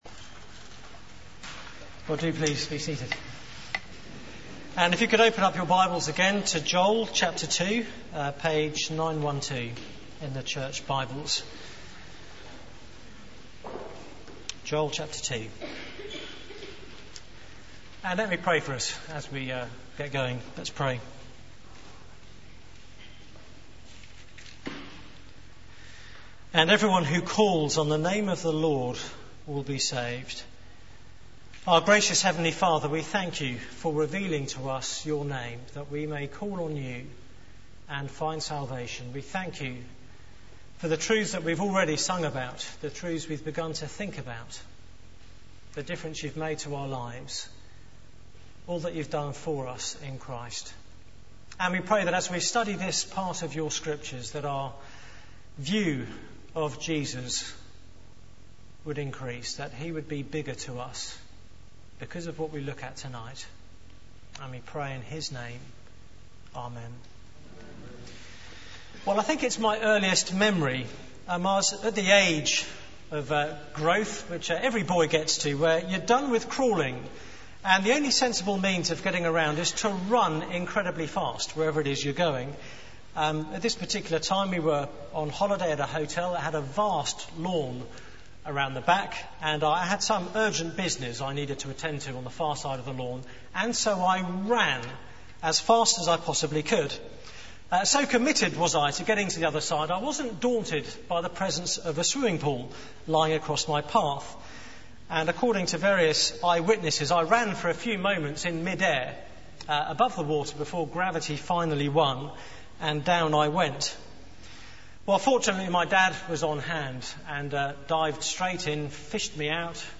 Media for 9:15am Service on Sun 12th Oct 2008 18:30 Speaker: Passage: Joel 2 Series: A Day is Coming Theme: Come Back to the Lord Sermon Slides Open Search the media library There are recordings here going back several years.